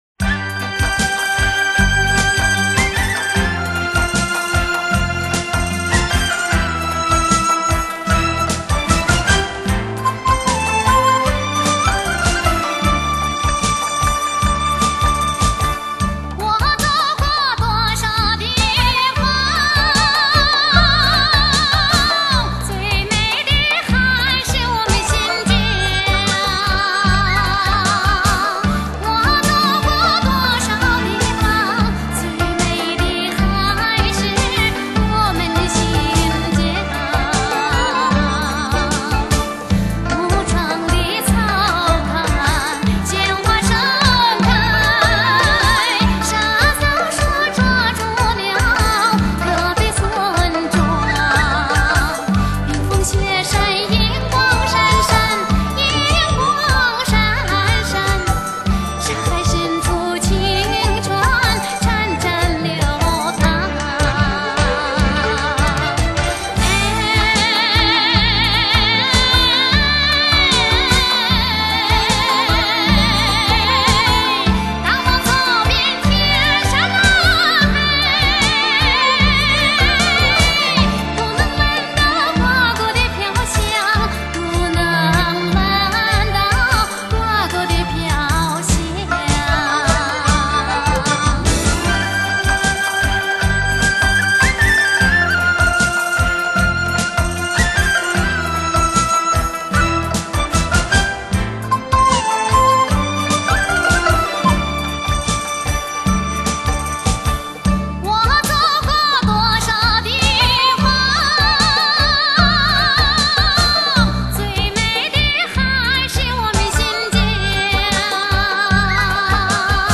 维吾尔族歌唱家、国家一级演员。